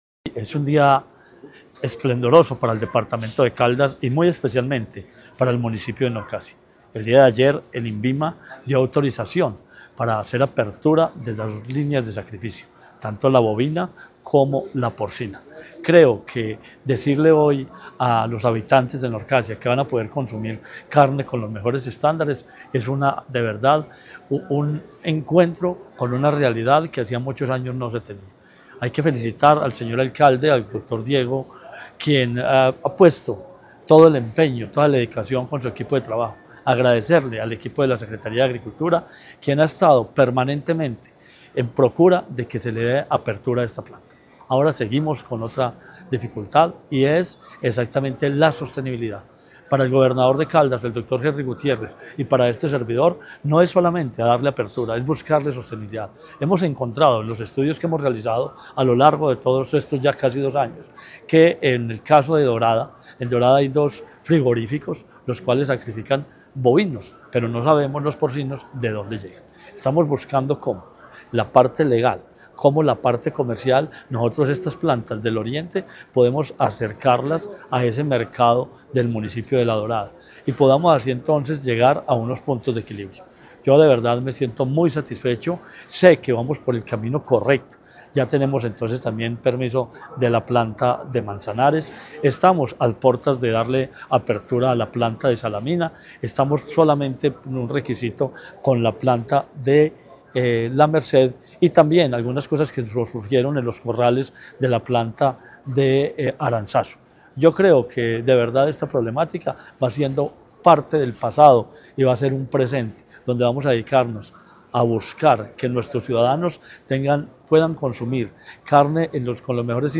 Marino Murillo Franco, secretario de Agricultura y Desarrollo Rural.